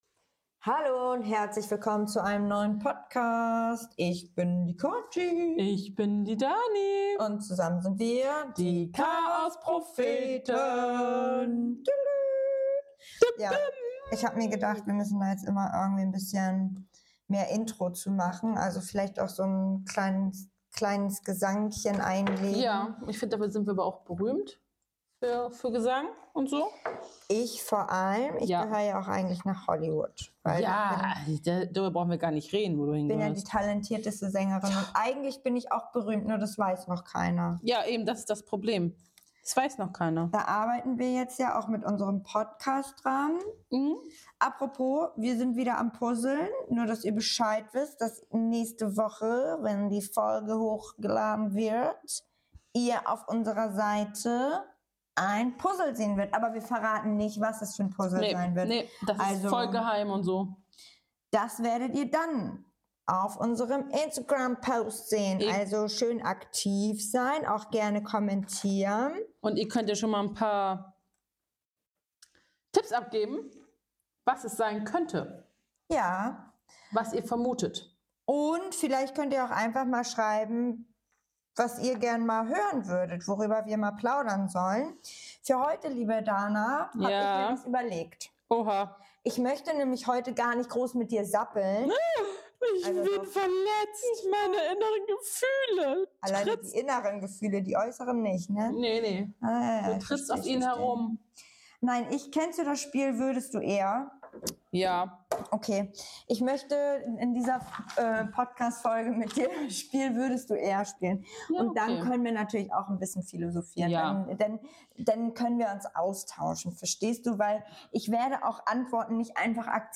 Ob peinliche Entscheidungen, völlig unrealistische Alltagssituationen oder philosophische Gedankensprünge: Die beiden lachen, diskutieren und verraten ganz nebenbei ein paar überraschende Seiten von sich.